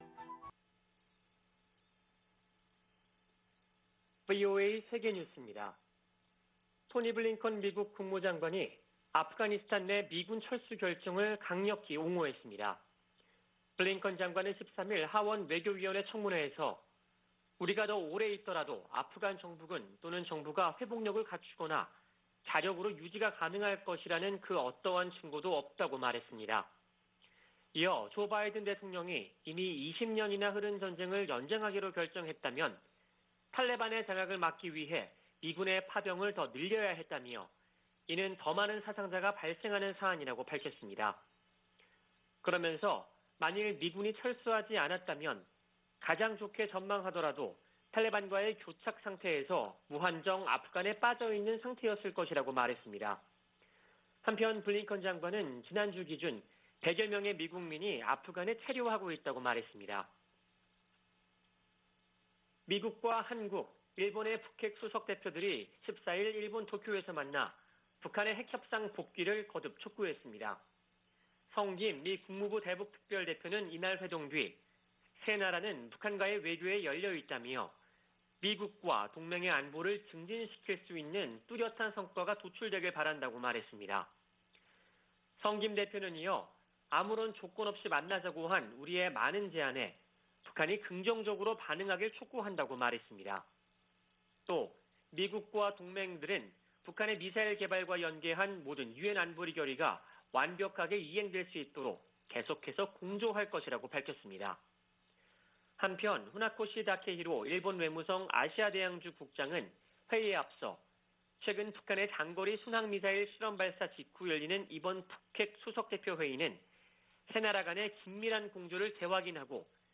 VOA 한국어 아침 뉴스 프로그램 '워싱턴 뉴스 광장' 2021년 9월 15일 방송입니다. 성 김 미국 대북특별대표는 미국은 북한에 적대적 의도가 없다며 북한이 대화 제의에 호응할 것을 거듭 촉구했습니다. 북한의 신형 장거리 순항미사일 시험발사는 도발이라기 보다는 무기체계 강화의 일환이라고 미국의 전문가들이 평가했습니다. 북한의 미사일 발사는 주민들의 식량을 뺏는 행위라고 국제인권단체가 비판했습니다.